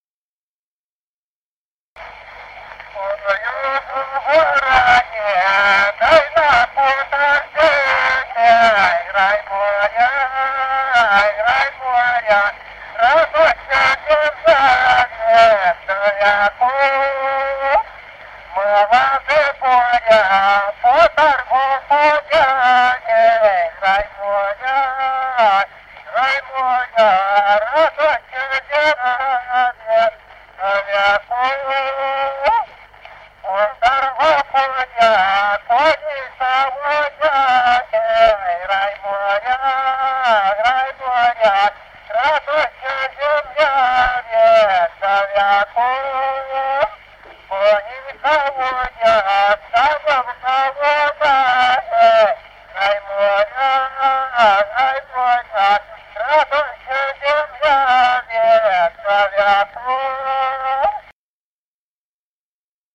Народные песни Стародубского района «Чтой у городе», новогодняя щедровная.
д. Камень.